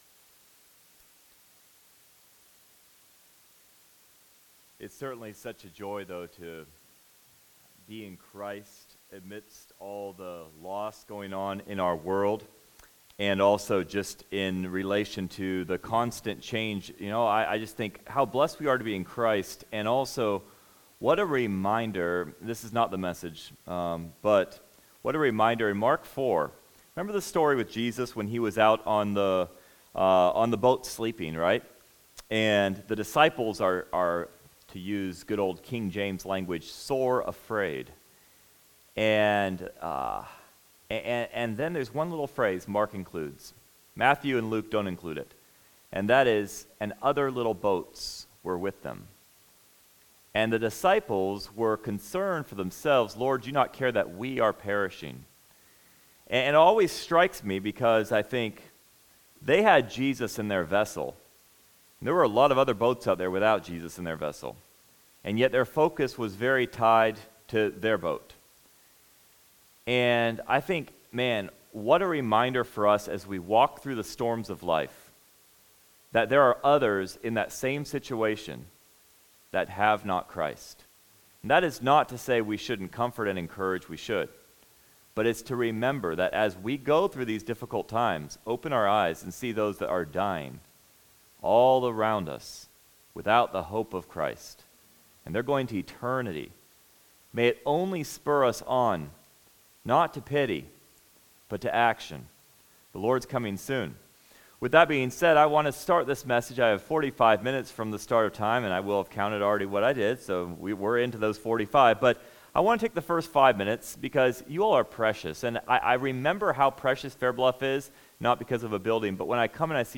Special Ministry Passage: 2 Cor 2:14-17 Service Type: Sunday Topics